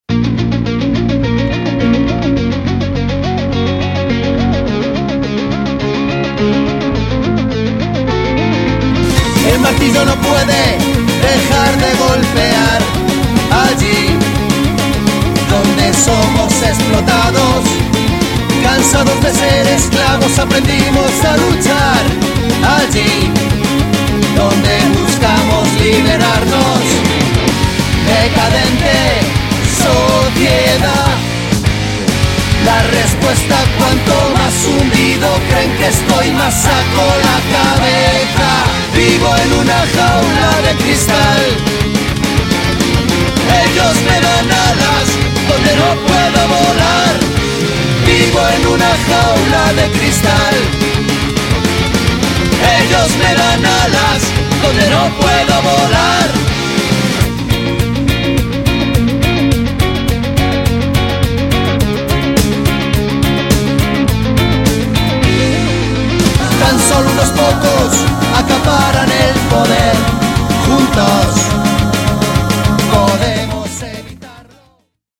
そのサウンドはストレートで全開、CDからも充分に伝わるパワーに熱くなることは必至！
そのエキゾチックなメロディが東欧ジプシー音楽風味を色漬け、新鮮さをプラスしている。